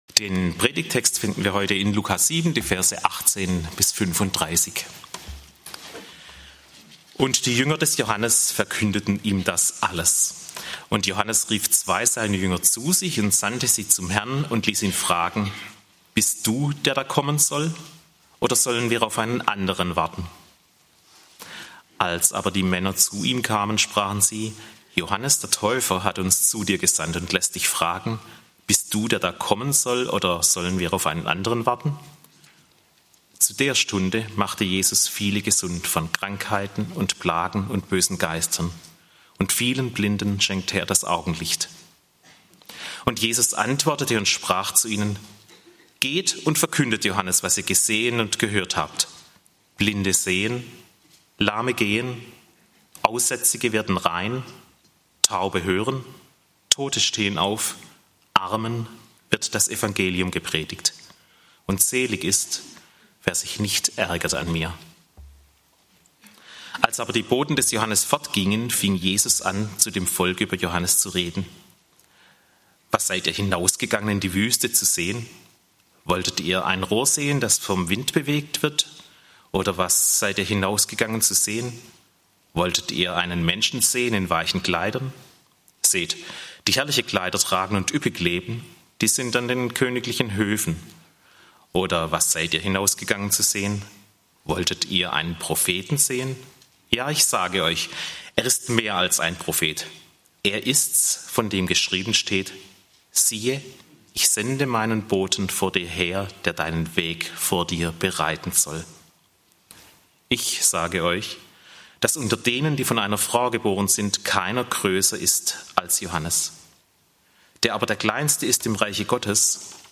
Wenn sich alles um Jesus dreht - Johannes der Täufer (Lk. 7, 18-35) - Gottesdienst